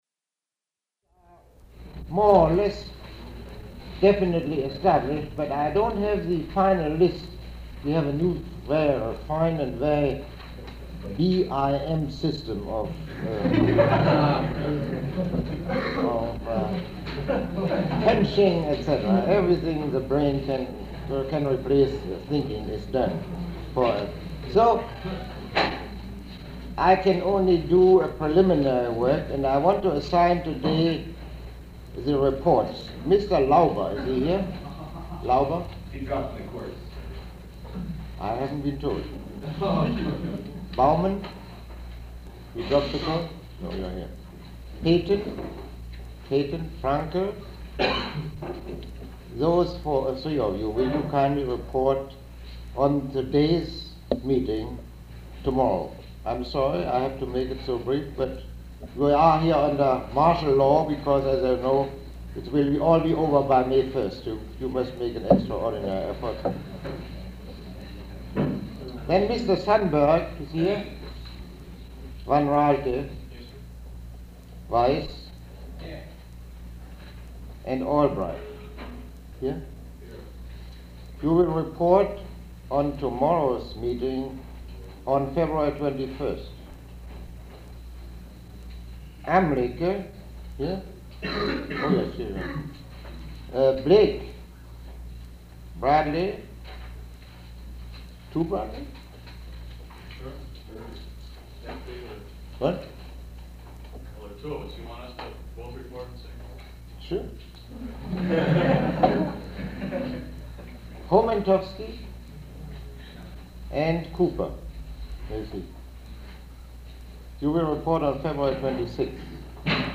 Lecture 04
Eugen Rosenstock-Huessy Live!